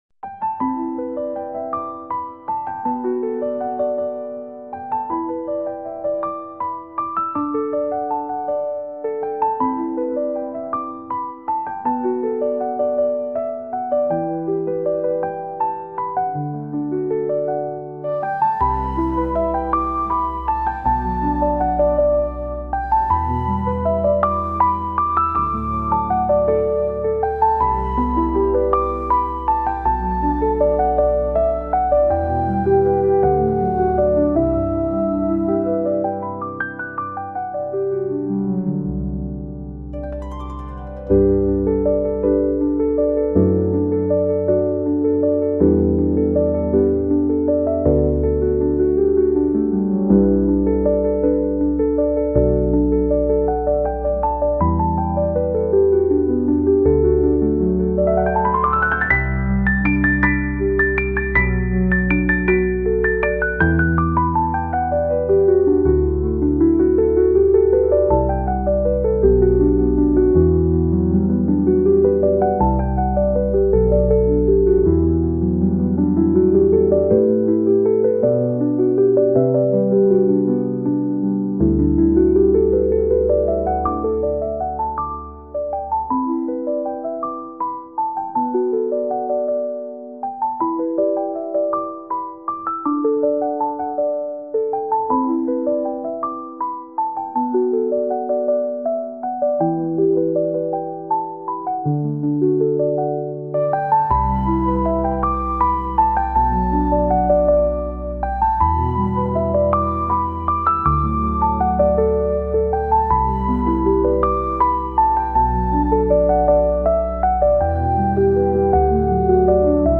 Sunset Piano & Flute Music
a beautiful and serene duet for piano and flute